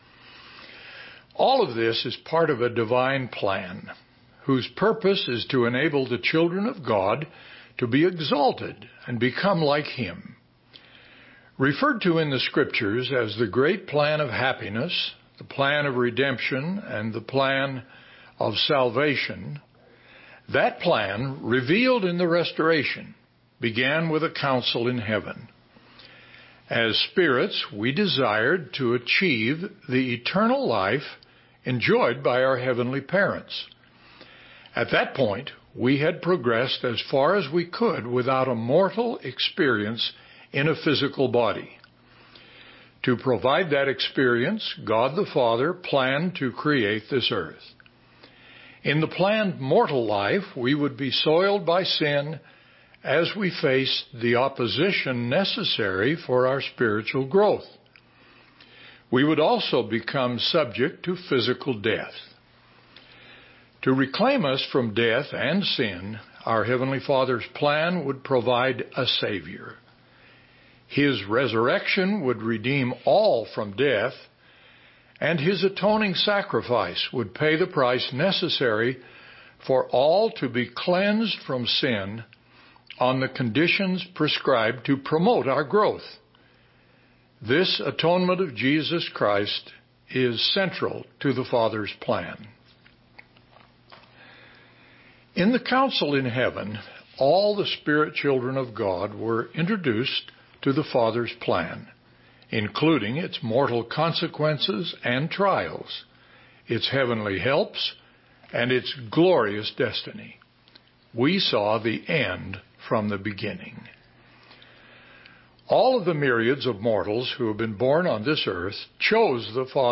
Conference Reports